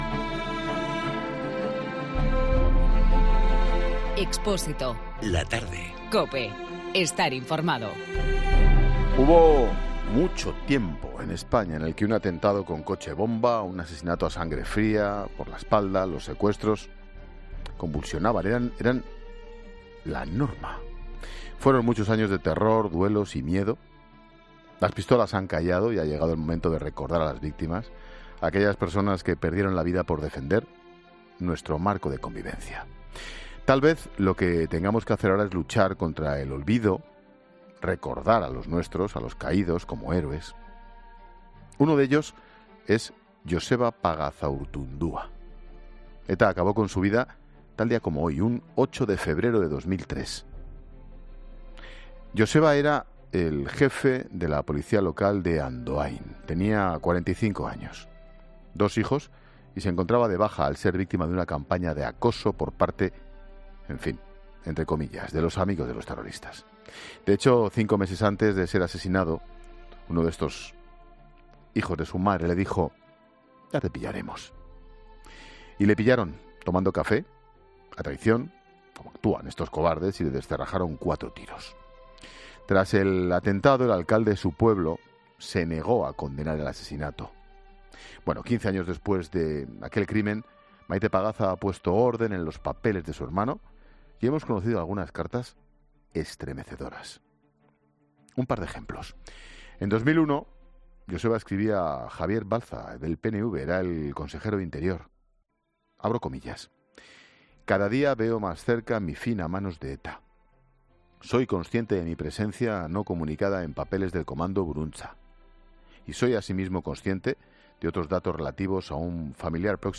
En 'La Tarde' hemos hablado con Maite Pagazaurtundua, hermana de Joseba
Lo hacían para que les vieran como gente mala, y como a gente a la que se podía eliminar, ESCUCHA LA ENTREVISTA COMPLETA | Maite Pagazaurtundua en 'La Tarde' La sociedad vasca y navarra vivía en el terror.